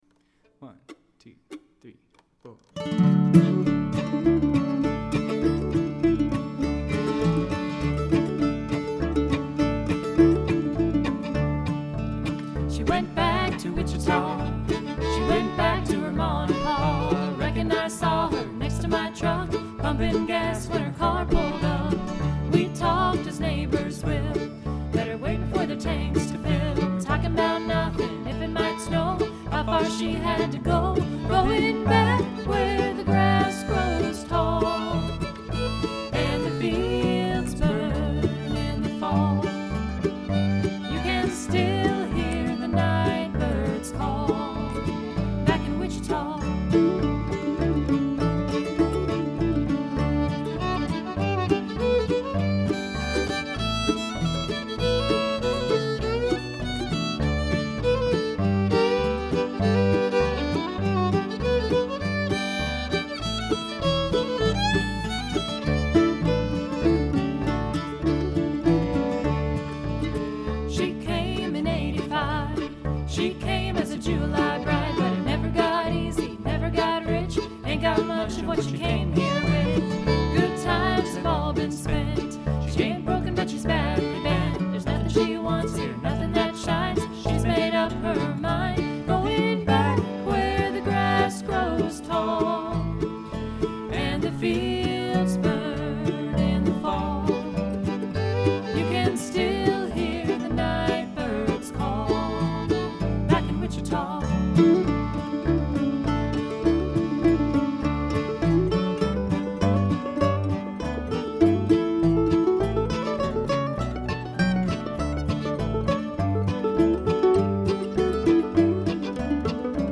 fiddle and vocals
guitar and vocals
extraordinary vocals
mandolin and vocals